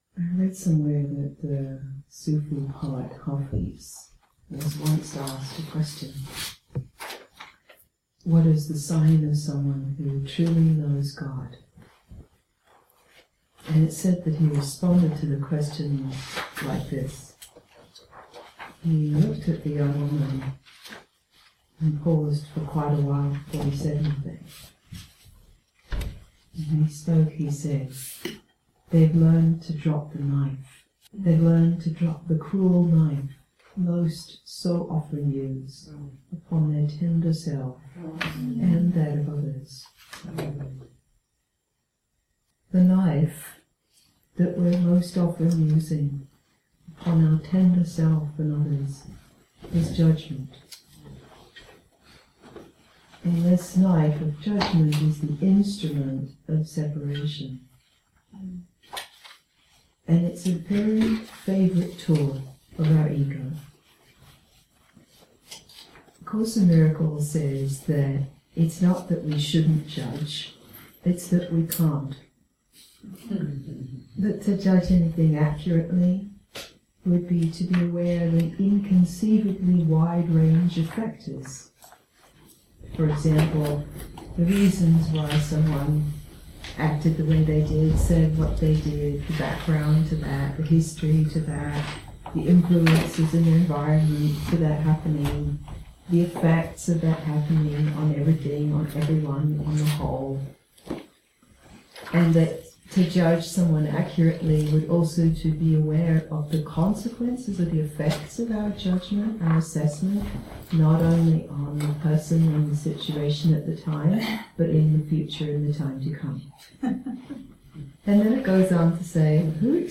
These teachings were recorded live in 2014.
The audio quality is not perfect but the content is good so that is why this is a free gift!